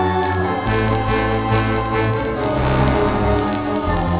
fat bottom hits and the little flute and